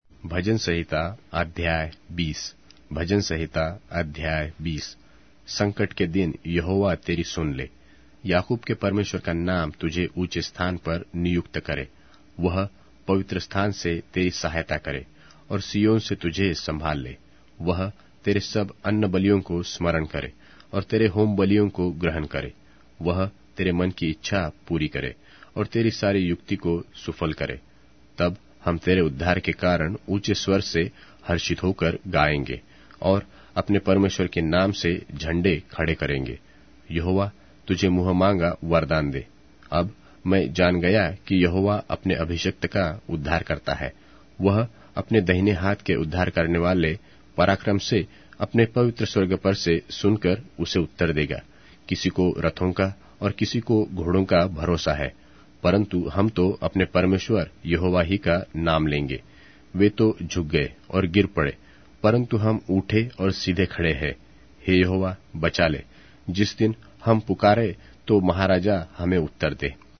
Hindi Audio Bible - Psalms 12 in Ervbn bible version